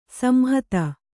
♪ samhata